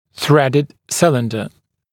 [‘θredɪd ‘sɪlɪndə][‘срэдид ‘силиндэ]цилиндр с резьбой